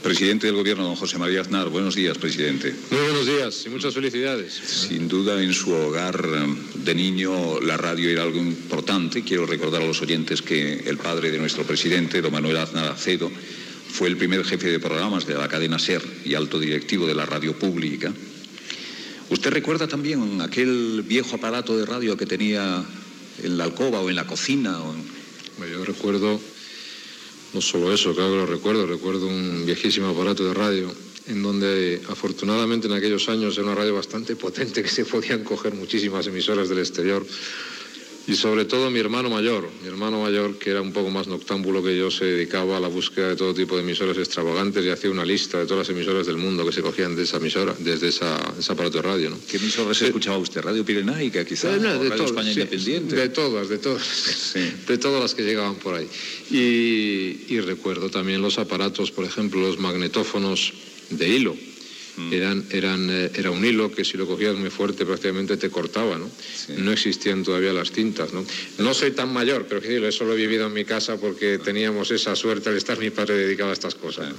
Intervenció del president del govern espanyol José María Aznar en el 25è aniversari del programa
Info-entreteniment